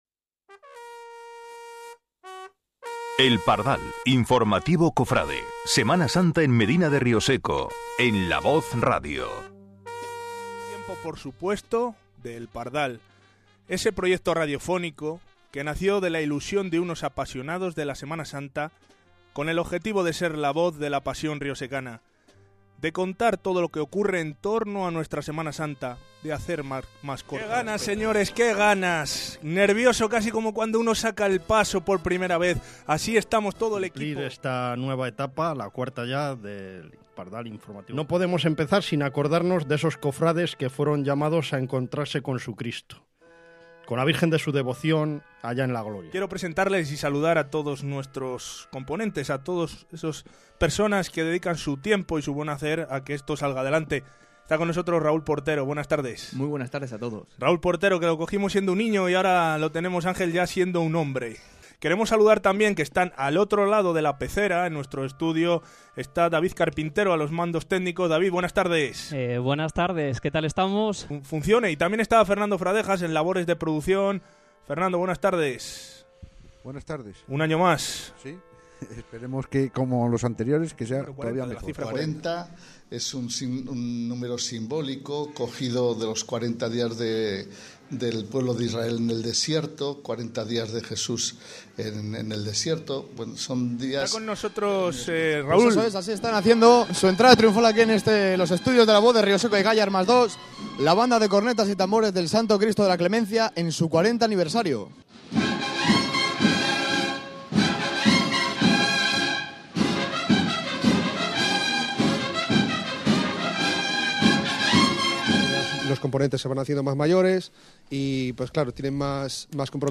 Setenta minutos muy intensos que recogen las más de doscientas voces que pasaron por los seis programas emitidos en La Voz Radio. Momentos muy emotivos, donde la lágrima asoma con facilidad, algunos instantes más divertidos, entrevistas, reportajes, la mejor música cofrade…